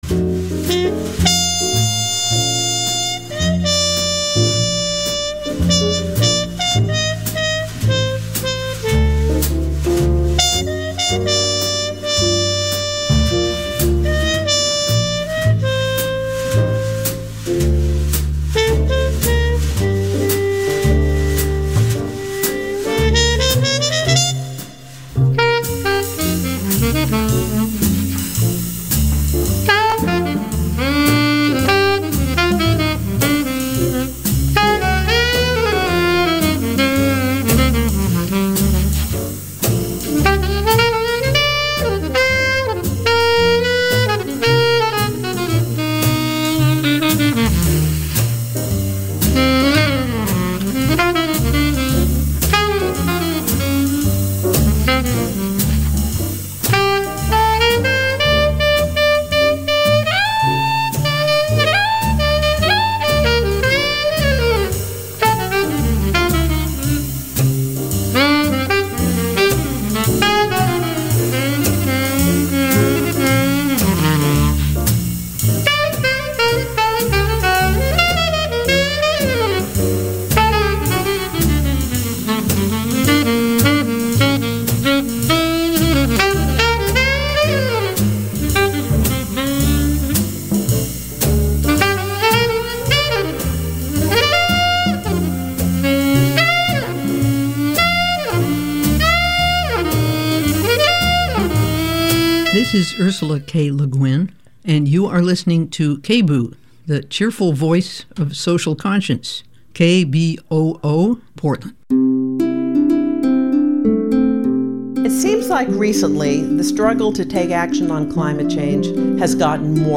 INVESTIGATIVE News Radio